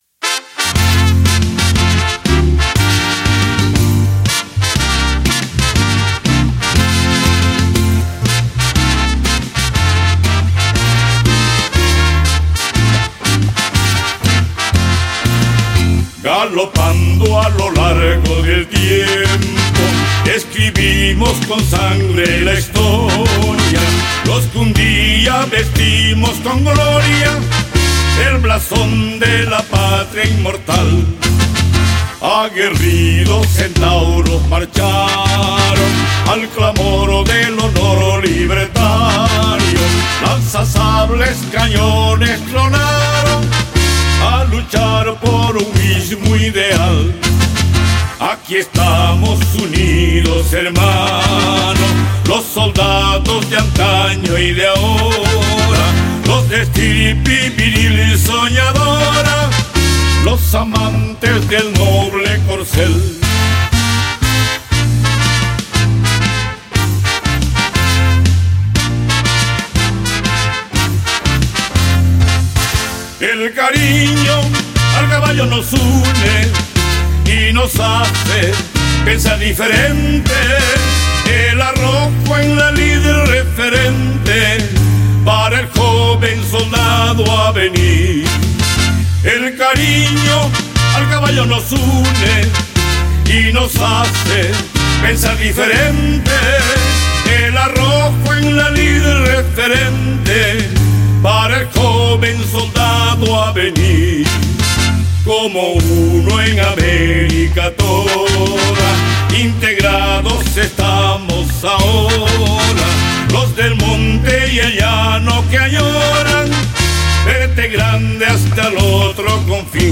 6b HIMNO DE LA CONFEDERACIÓN SUDAMERICANA DE OFICIALES DE CABALLERÍA (Cantado)
6bp-himno-a-la-consocab-cantado.mp3